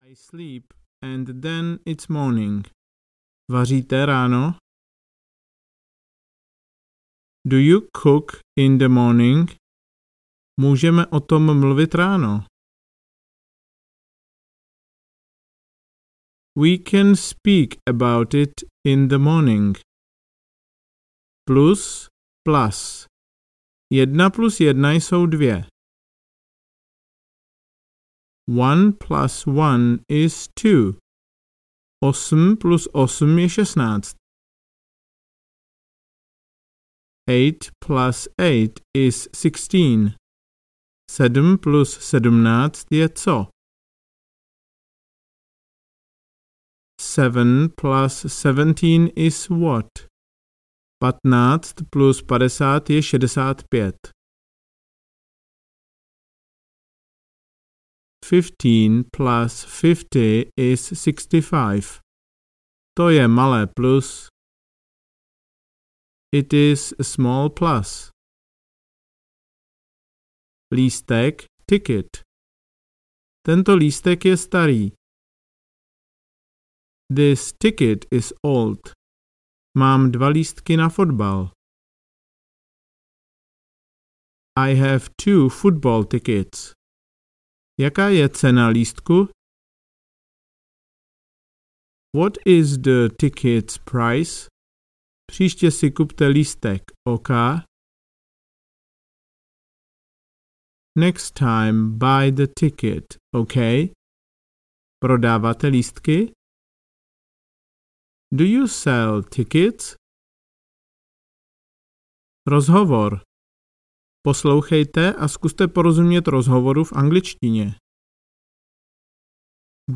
Začátečník na dovolené audiokniha
Ukázka z knihy